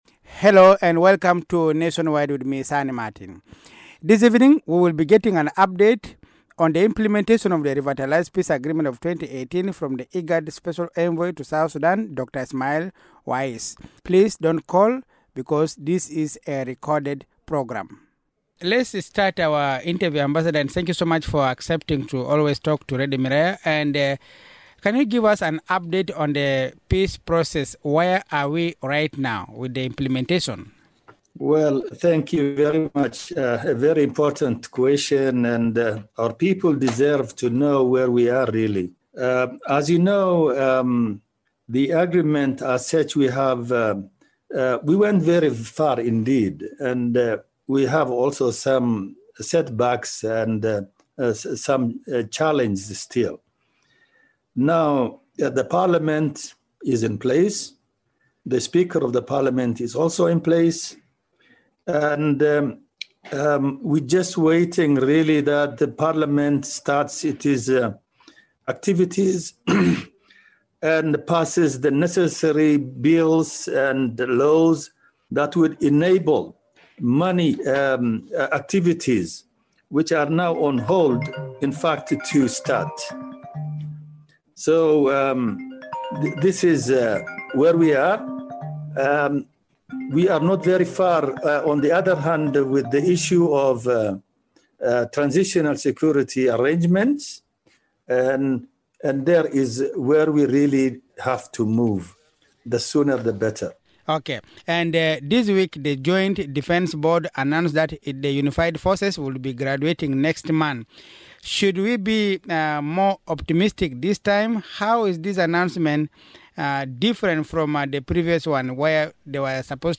In this 30" interview Ambassador Wais also updates on the peace process and raises concern about the sub-national violence witnessed in parts of the country.